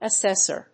音節as・sés・sor 発音記号・読み方
/‐sɚ(米国英語), ‐sə(英国英語)/
フリガナアセサー